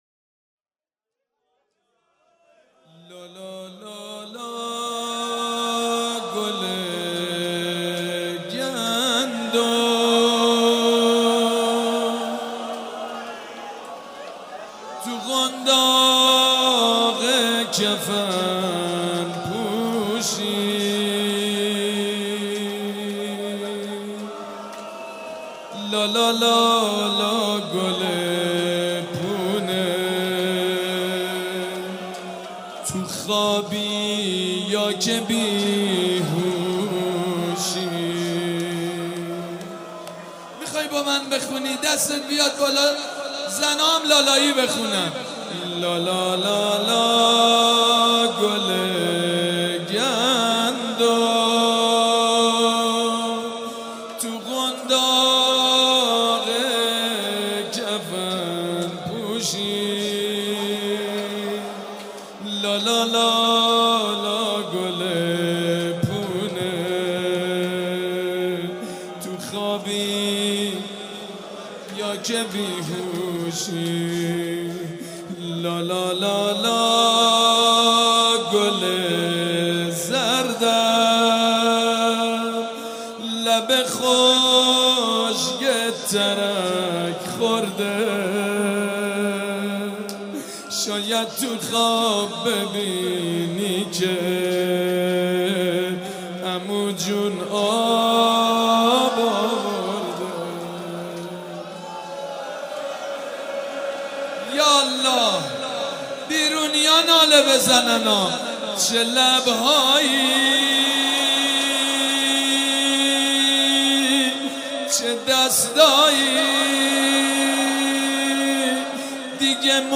شب هفتم محرم الحرام‌ شنبه ۱7 مهرماه ۱۳۹۵ هيئت ريحانة الحسين(س)
سبک اثــر روضه مداح حاج سید مجید بنی فاطمه